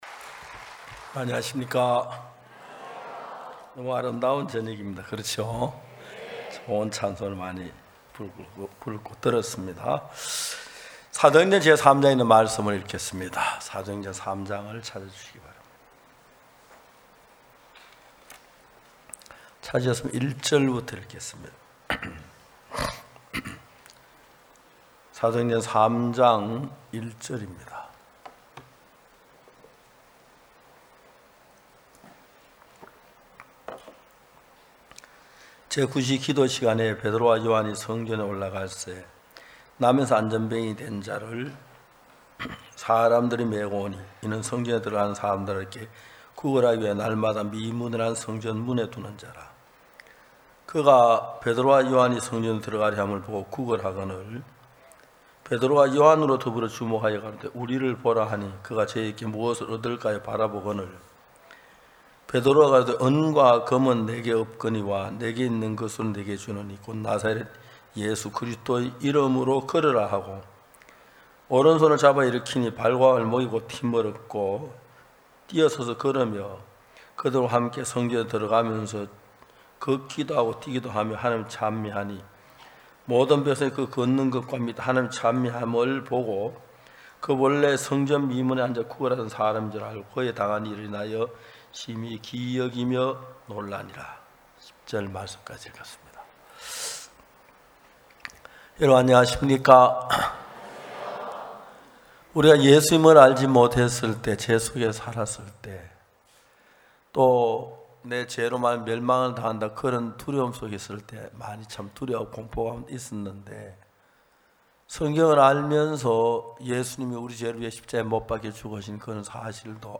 2024 후반기 서울 성경세미나